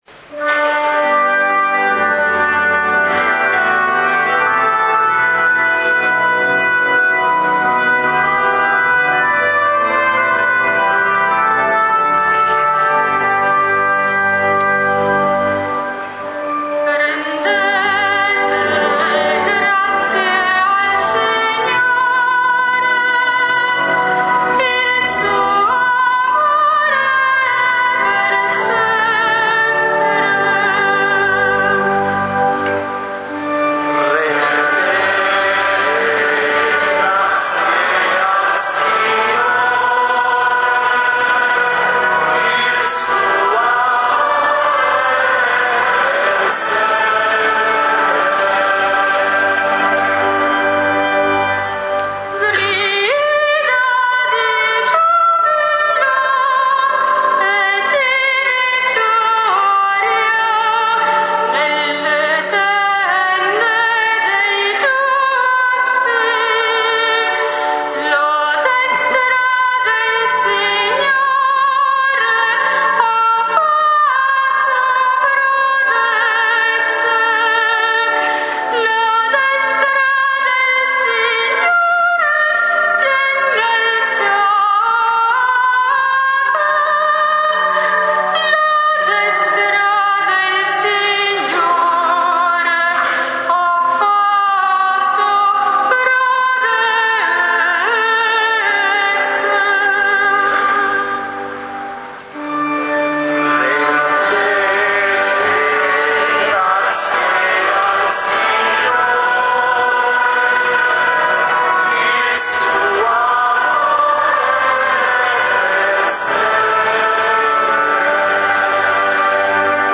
Gallery >> Audio >> Audio2013 >> Ingresso Arcivescovo Pennisi >> mp3-Salmo Responsoriale
mp3-Salmo Responsoriale